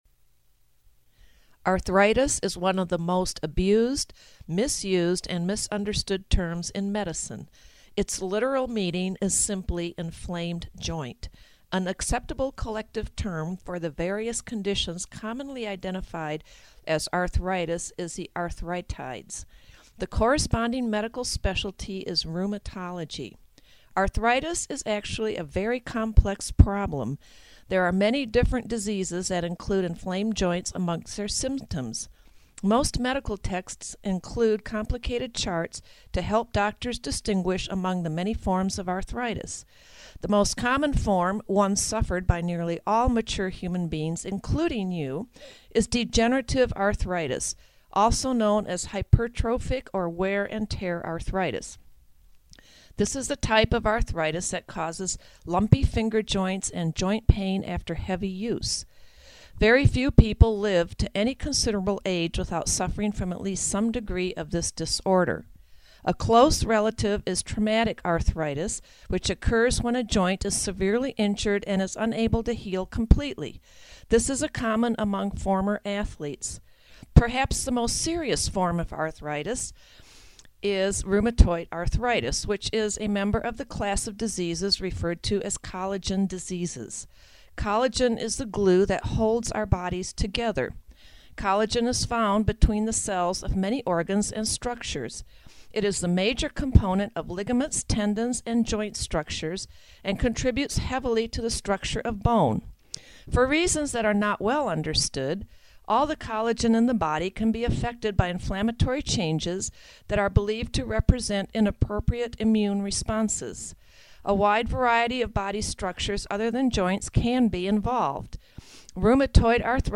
Monologue 1 Audio only -